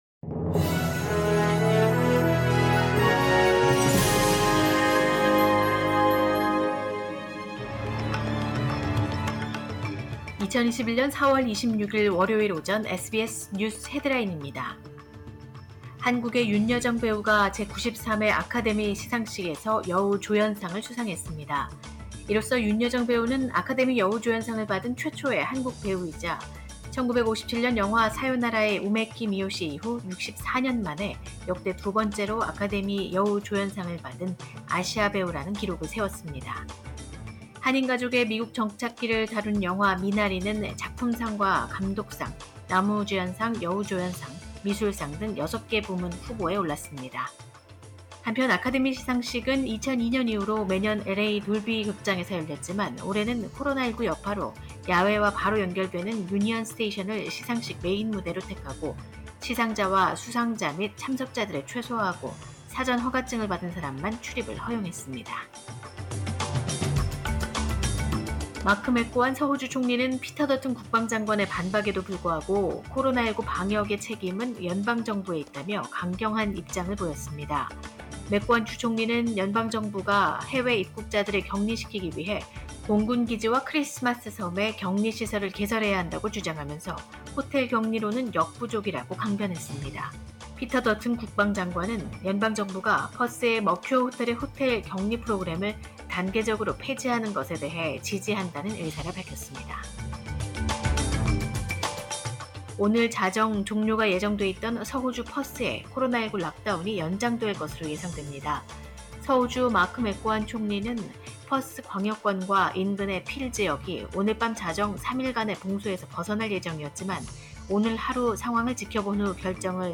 2021년 4월 26일 월요일 오전의 SBS 뉴스 헤드라인입니다.